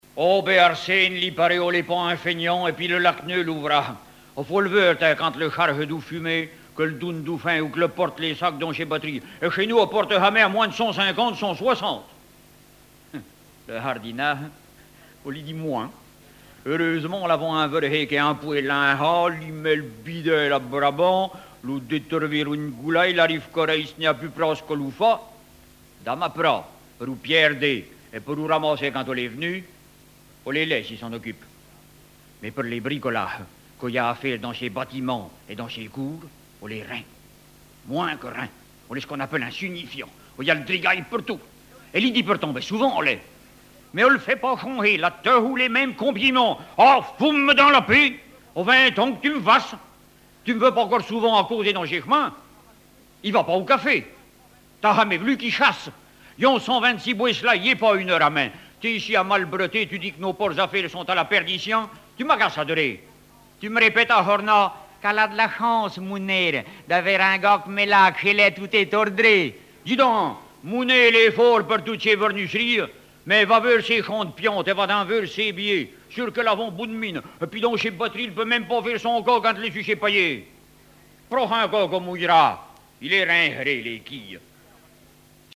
Genre sketch
histoires en patois poitevin